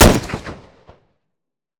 Much of the audio is still a work in progress but here are a few short samples of our sniper gunfire.
sfxsniper01.wav